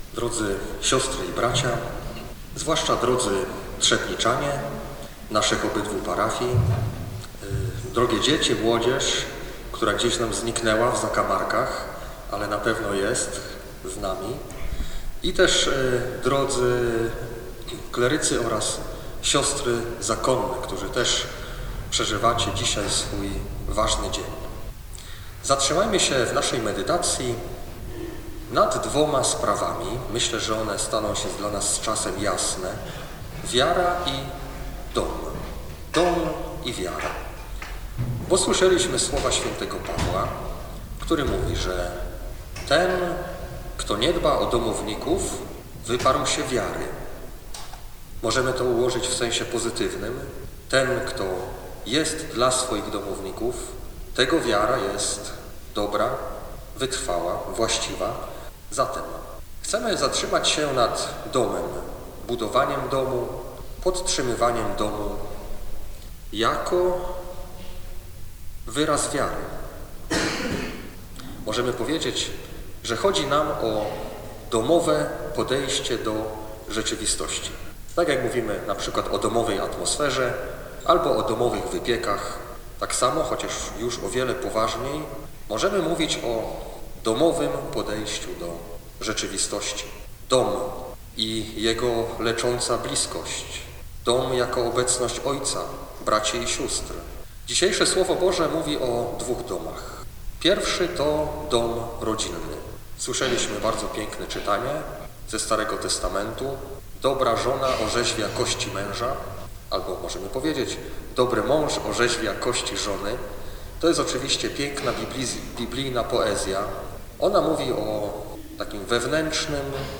Tłumy pielgrzymów śpiewając i wznosząc modlitwy, przemaszerowało z trzebnickiego rynku do Sanktuarium św. Jadwigi Śląskiej, gdzie bp Maciej Małyga sprawował uroczystą Mszę Świętą.
Zachęcamy też do wysłuchania homilii wygłoszonej przez bpa Macieja Małygę.